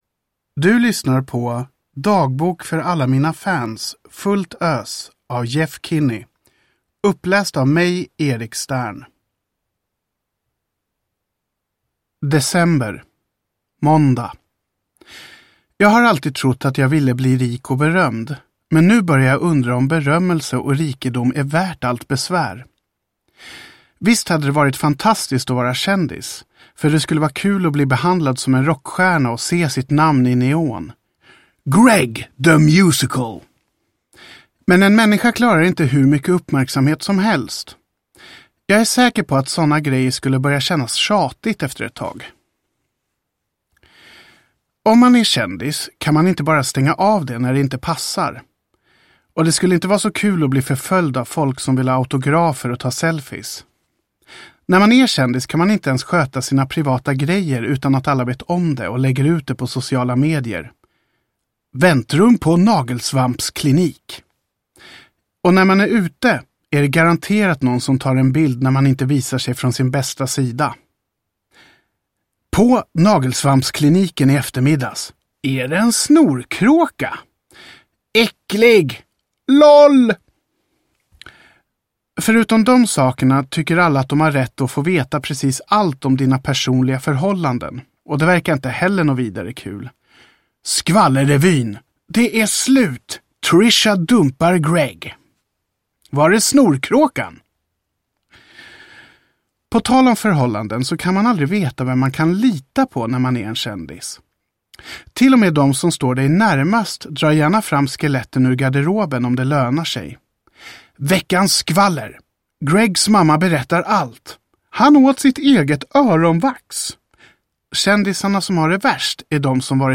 Fullt ös – Ljudbok – Laddas ner